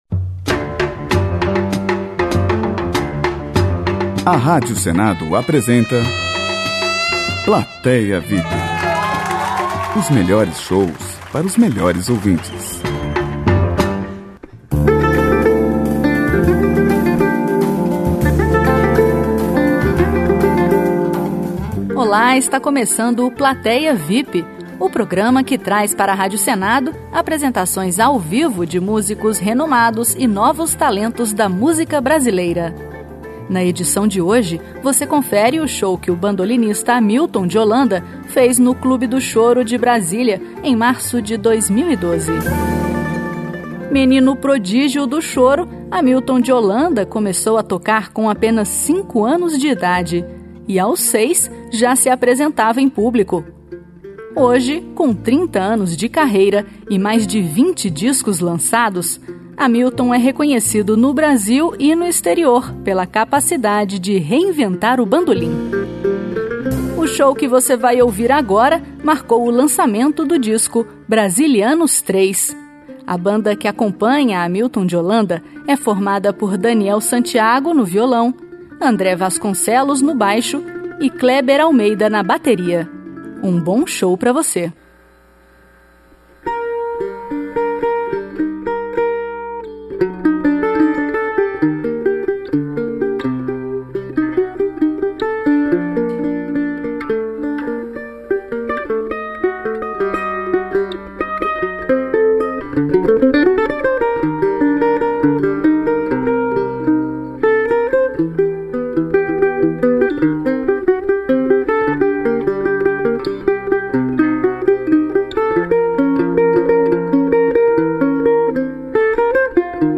Choro